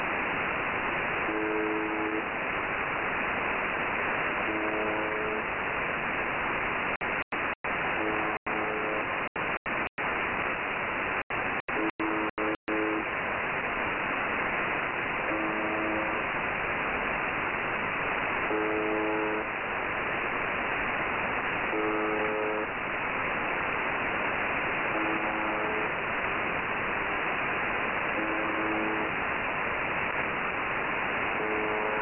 Ben, j'ai capté the buzzer (4625 kHz)
the-buzzer.mp3